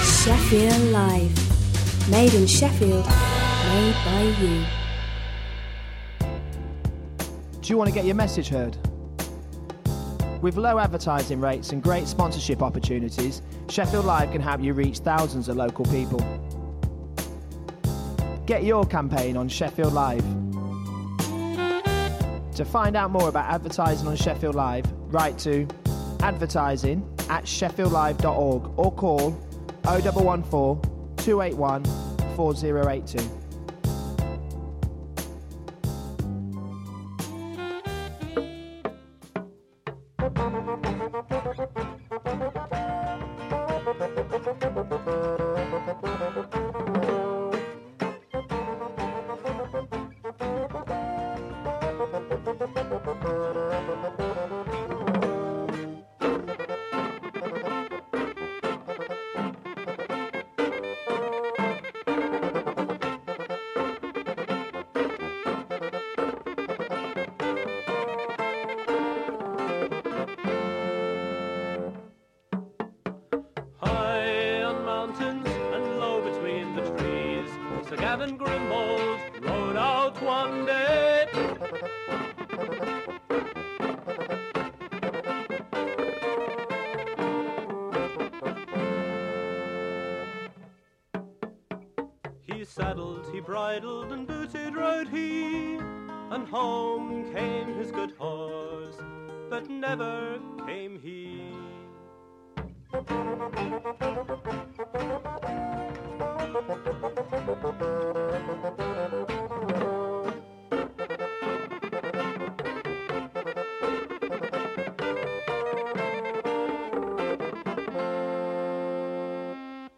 Playing the very best in urban music, new & old…